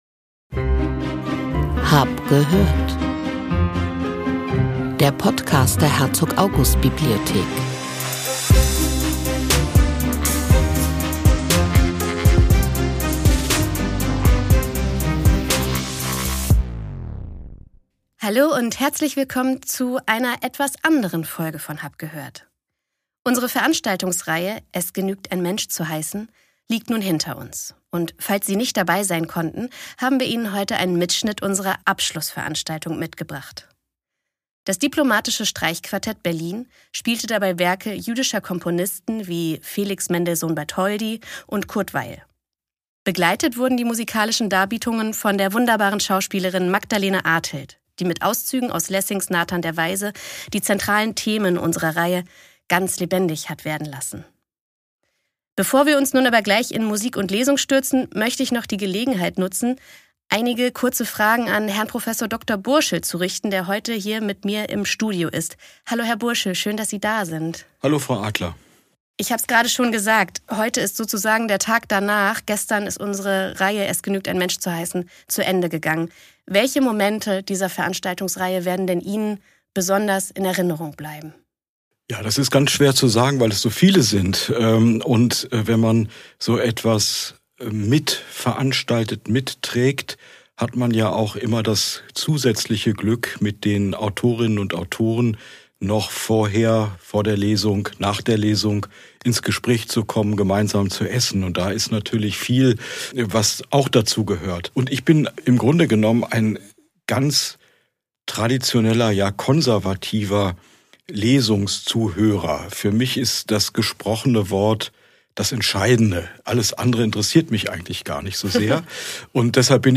Das Diplomatische Streichquartett
Wer dabei gewesen ist weiß, dass die Aufzeichnung Musik und Stimme nicht ganz gerecht werden kann – dennoch möchten wir die Inhalte und Eindrücke dieses Abends mit Ihnen teilen.